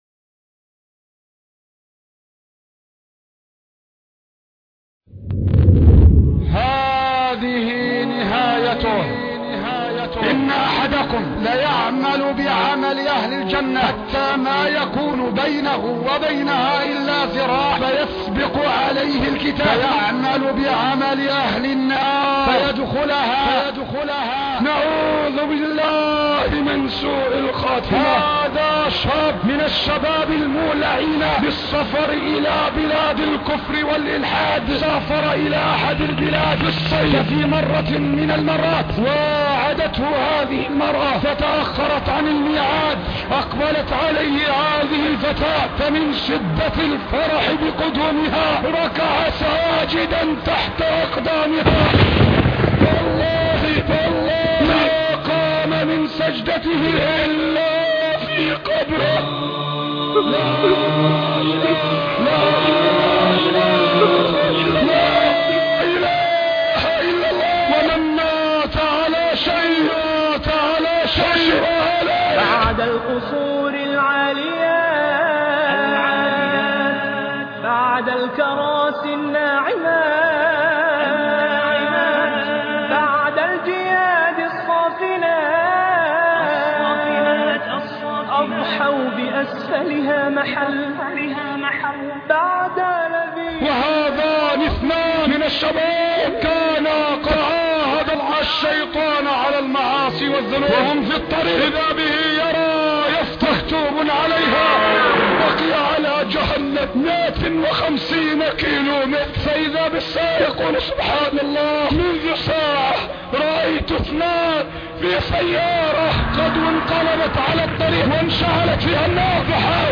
خطب صوتية